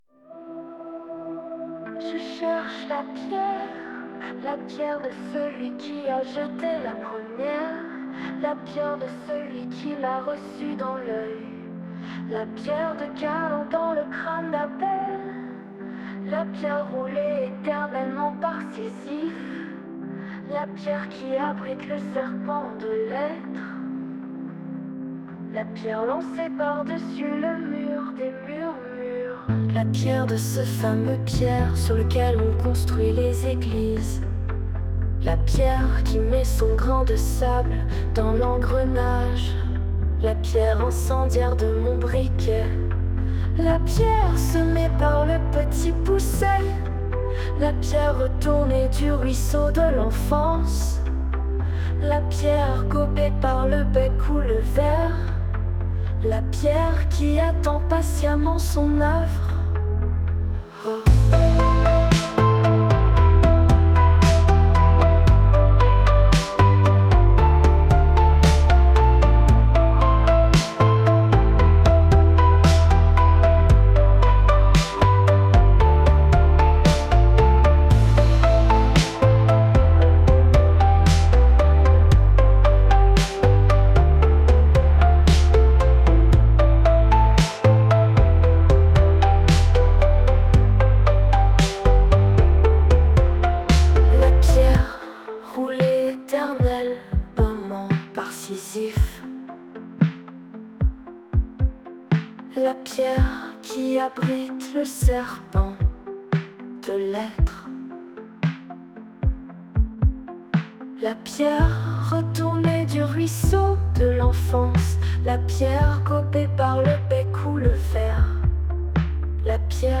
Je-cherche-la-pierre-Femme-dark-soft-rock-beats.mp3